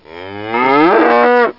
Amiga 8-bit Sampled Voice
moo.mp3